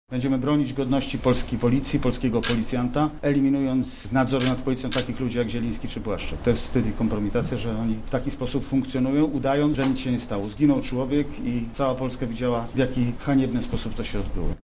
Taką decyzję ogłosił Grzegorz Schetyna na wczorajszej konferencji prasowej w Lublinie.